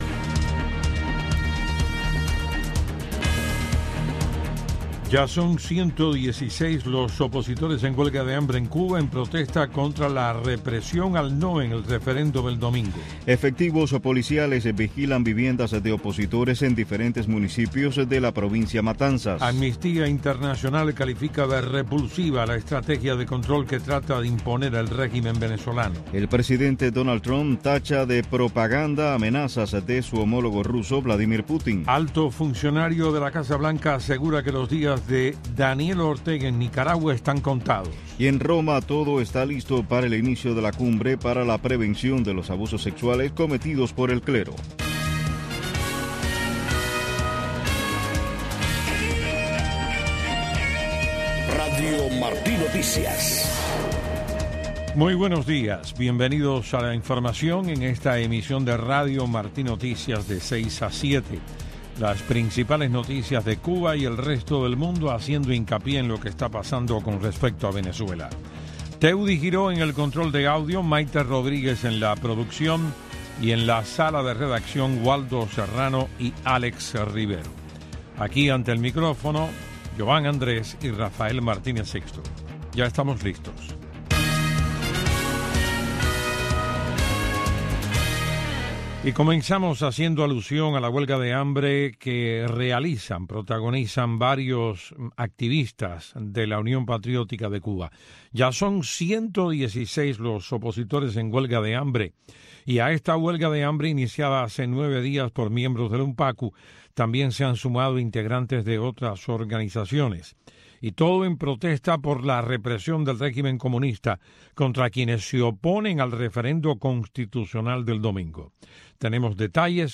Noticiero de Radio Martí 6:00 AM